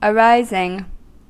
Ääntäminen
Ääntäminen US Tuntematon aksentti: IPA : /əˈrɑɪziŋ/ Haettu sana löytyi näillä lähdekielillä: englanti Arising on sanan arise partisiipin preesens.